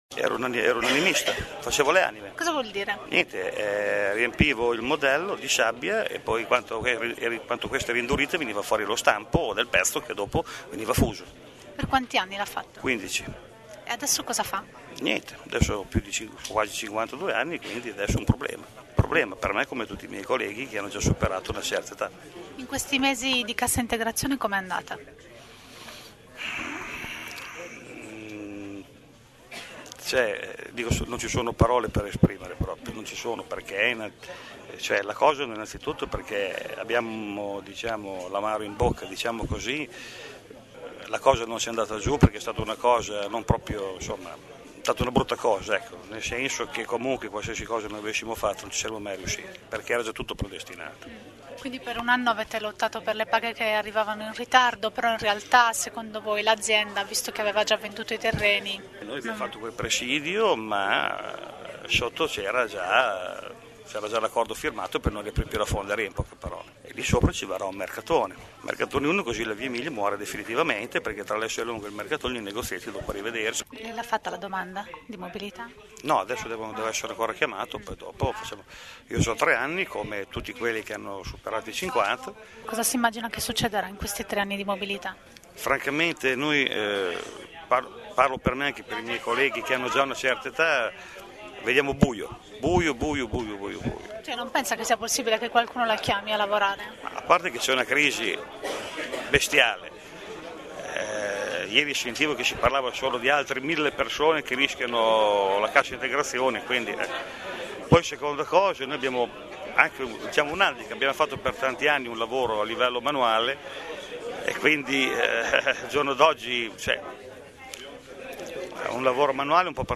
Ai nostri microfoni raccontano come stanno affrontando questo periodo